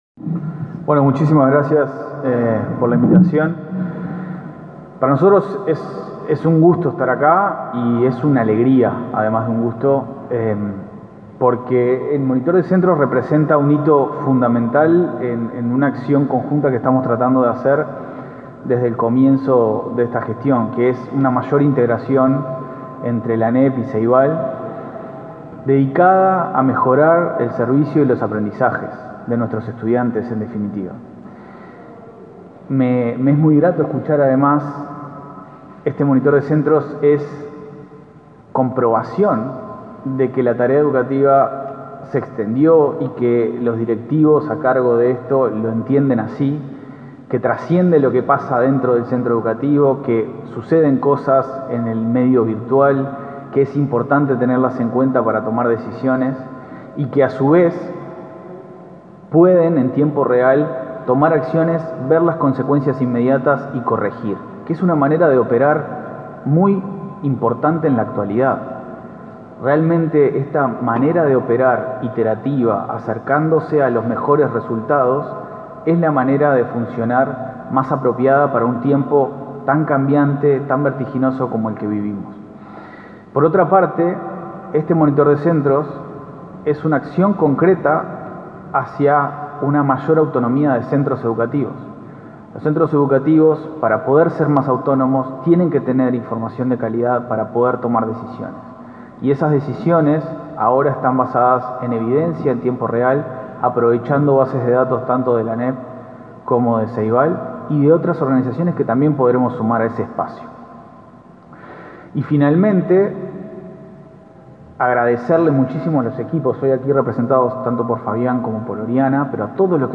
Palabras de los presidentes del Plan Ceibal y de la ANEP